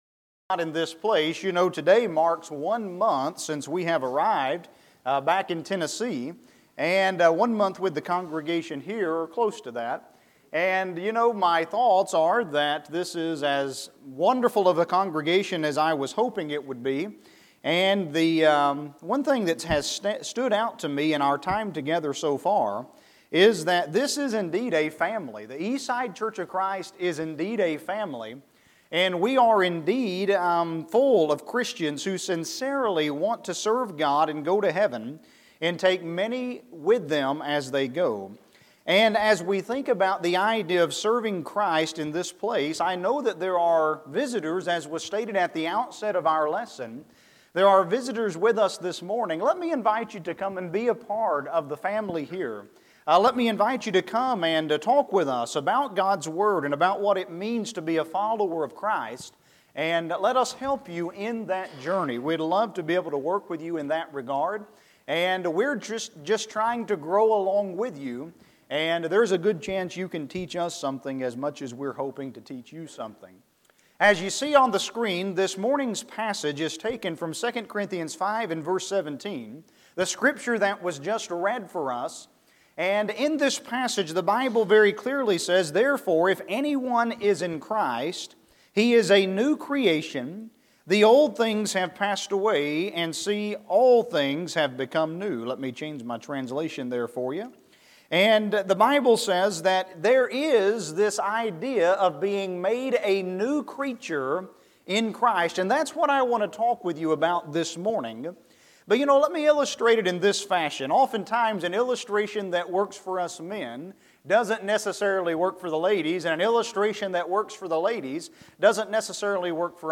2 Corinthians 5:17 Service Type: Sunday Morning « Can We Know That There is a God?